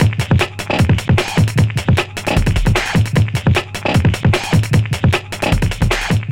drums02.wav